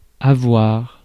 Ääntäminen
France (Paris): IPA: [a.vwaʁ]